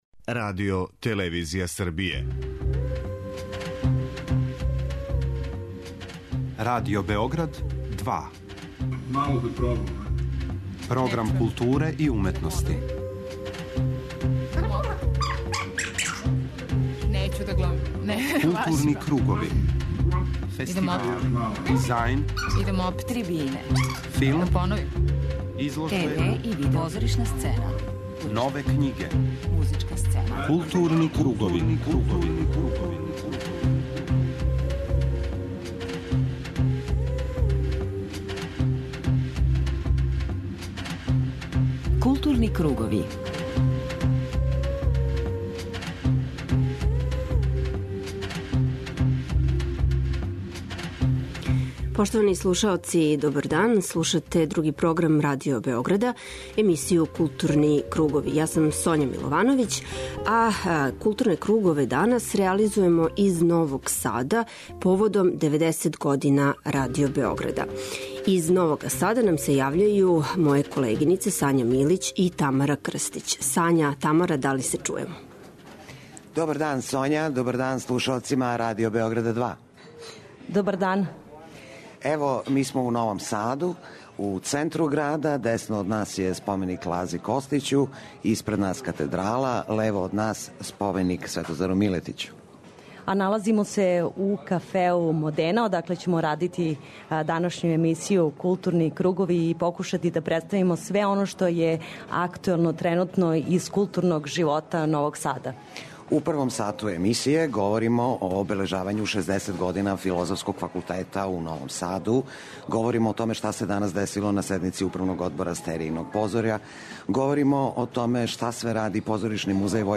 У оквиру обележавања 90 година Радио Београда, 'Културне кругове' емитујемо из Новог Сада.